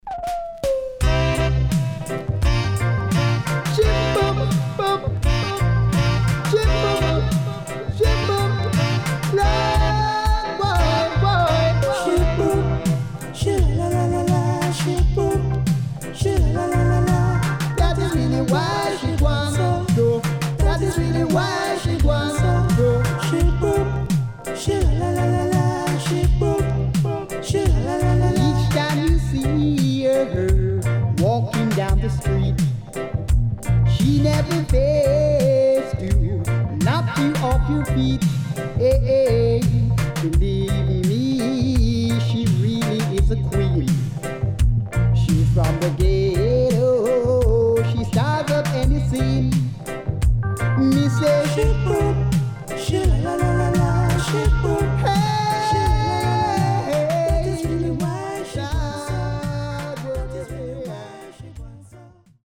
HOME > DISCO45 [DANCEHALL]
Nice Vocal.Good Condition
SIDE A:盤質は良好です。盤面はきれいです。